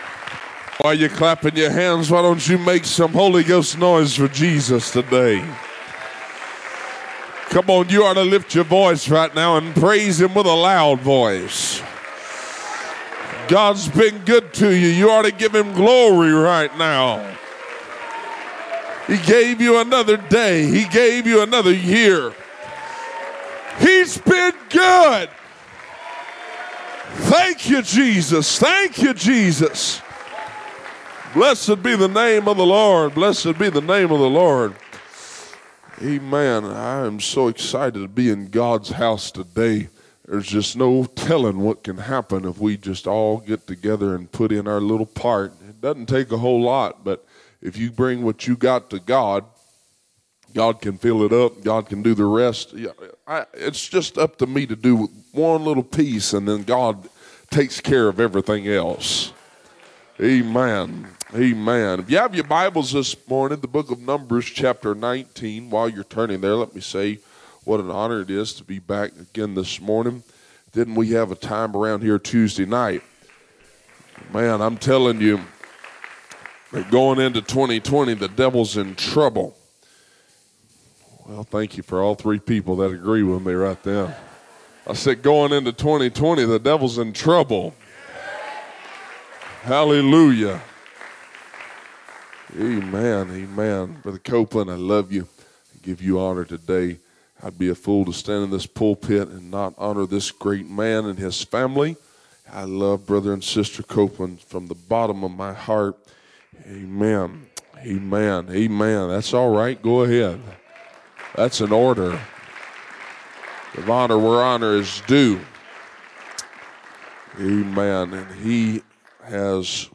First Pentecostal Church Preaching 2020